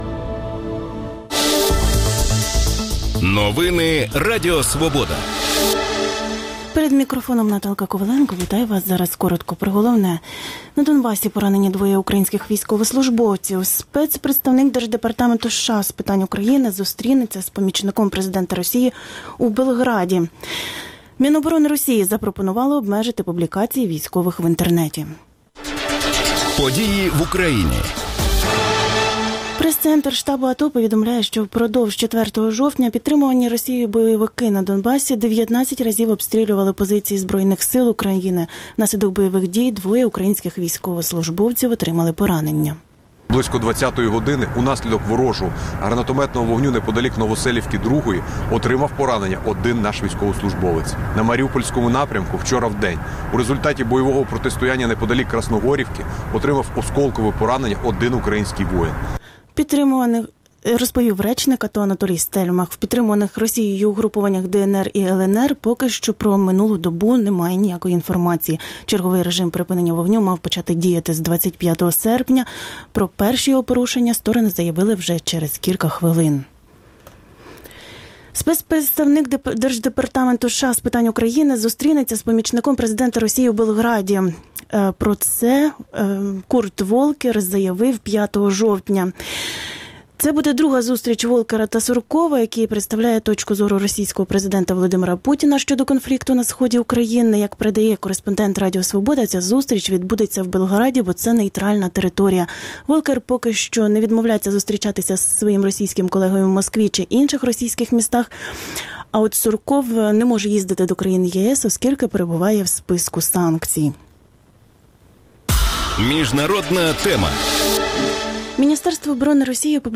Про це у Ранковій Свободі говоритимуть начальник департаменту спеціальних розслідувань Генеральної прокуратури України Сергій Горбатюк і народний депутат Ігор Луценко.